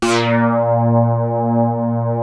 P.5 A#3 1.wav